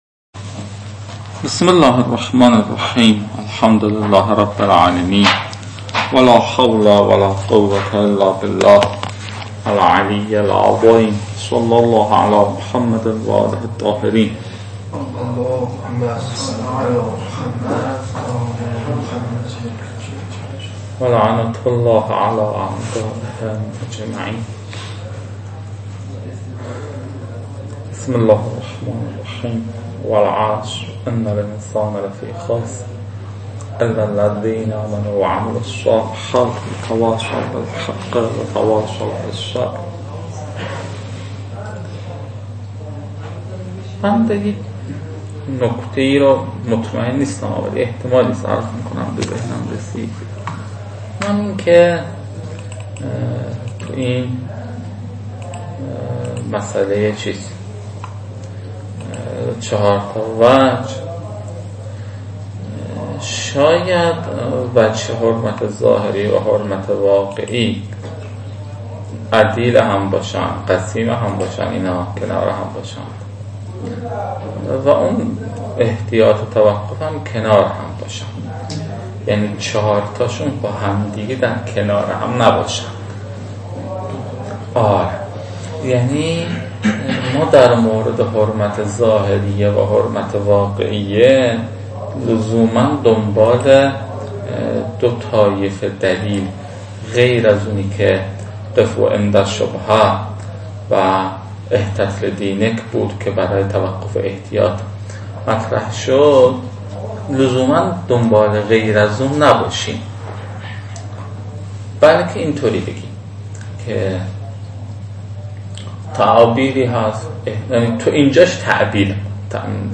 تدریس